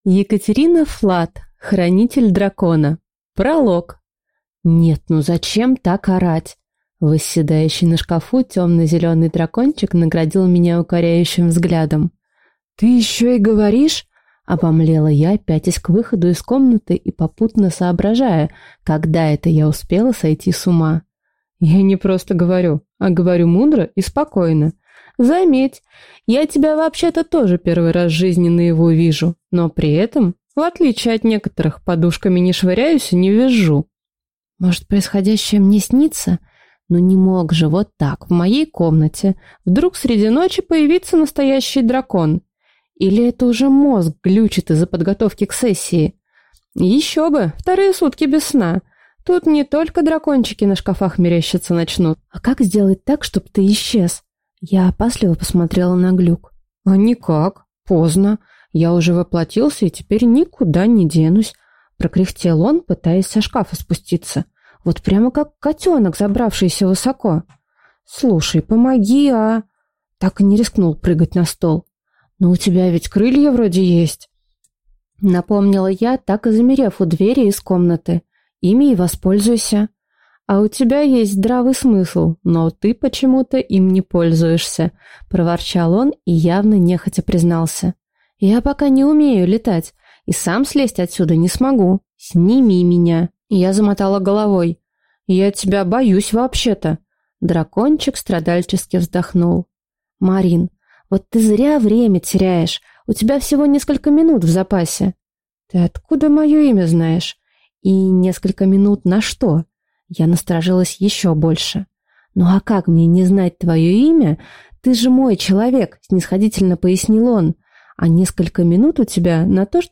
Аудиокнига Хранитель дракона | Библиотека аудиокниг